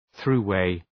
Προφορά
throughway.mp3